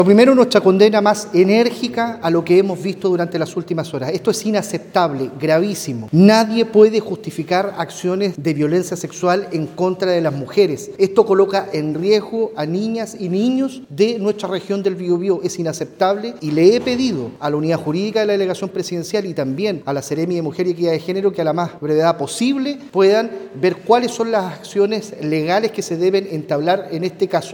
Por lo que, el delegado Presidencial del Bío Bío, Eduardo Pacheco, señaló que frente a una situación tan grave, están evaluando qué acciones legales pueden interponer contra el sujeto.